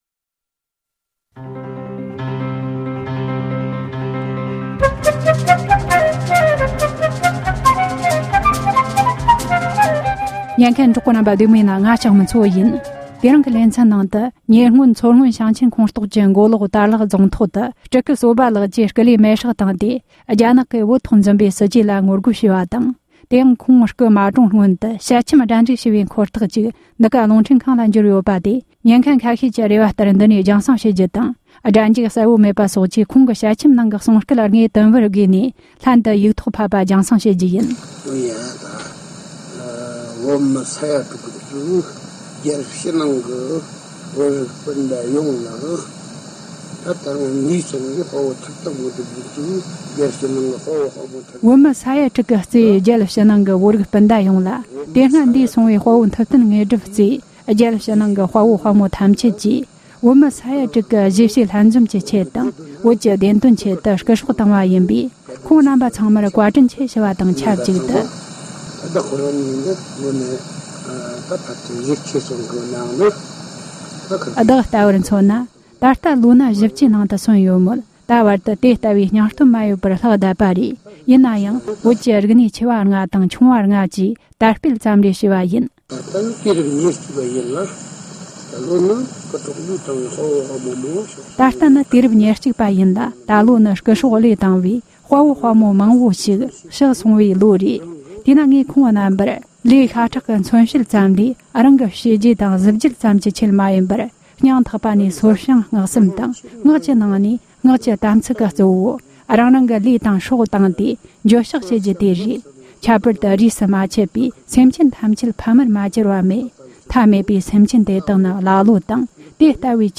ཞལ་ཆེམས་འཁོར་ཐག་སྒྲ་འཇུག